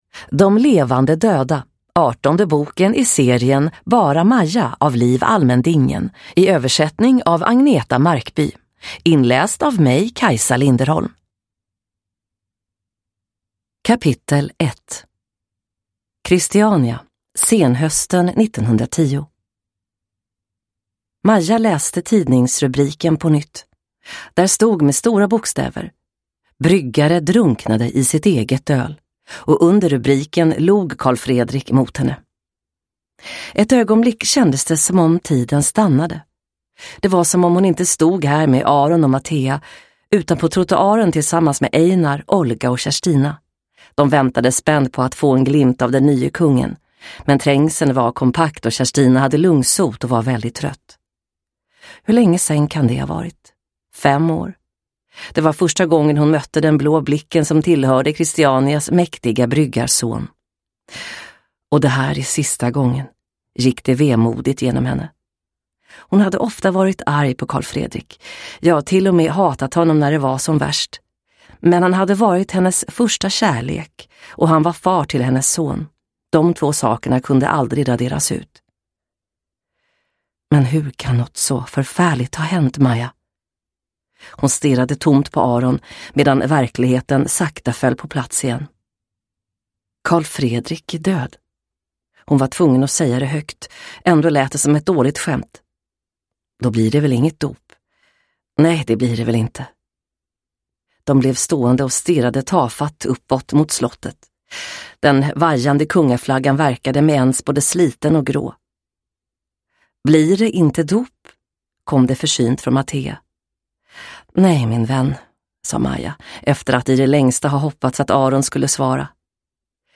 De levande döda – Ljudbok